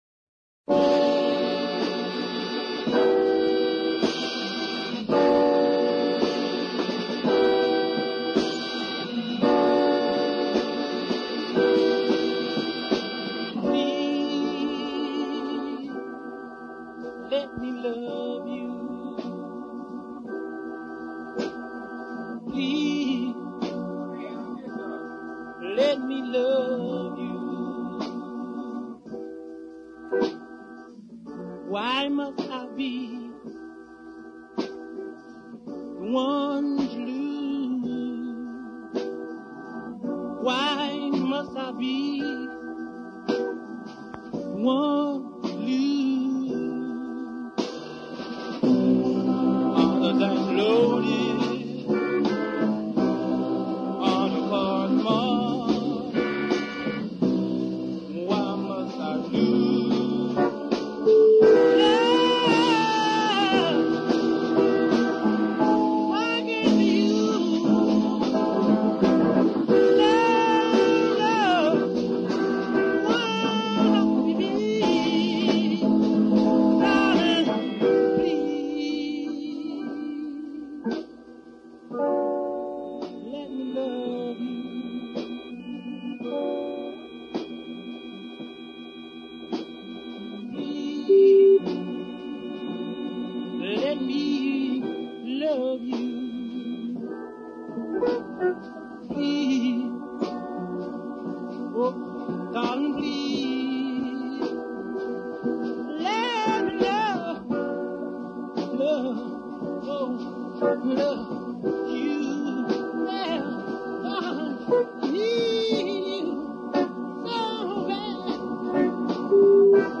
both of them feature a ballad side and an uptempo side